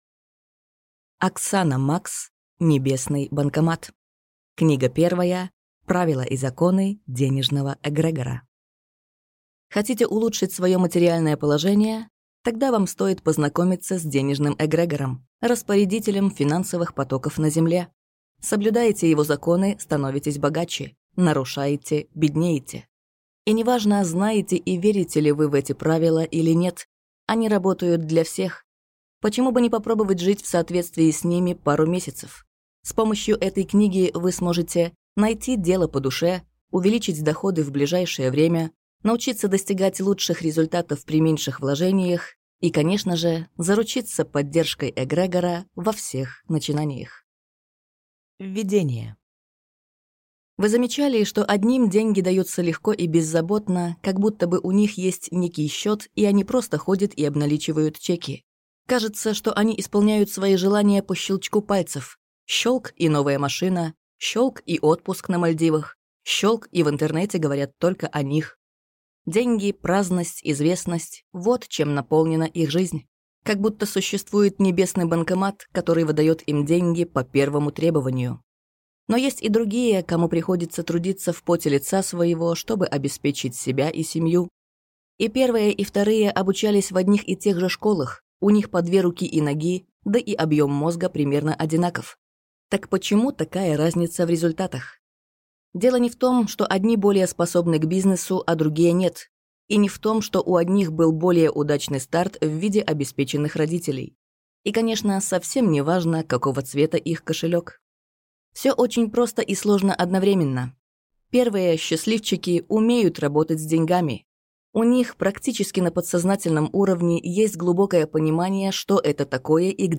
Аудиокнига Небесный банкомат. Книга 1. Правила и законы денежного эгрегора | Библиотека аудиокниг